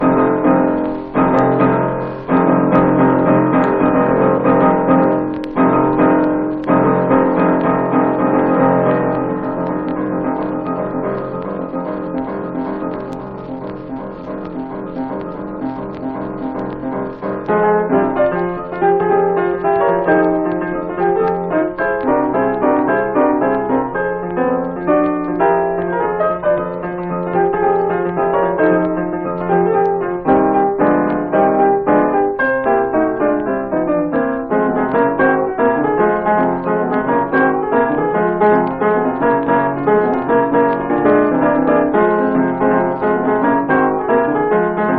Jazz, Blues, Ragtime　USA　12inchレコード　33rpm　Mono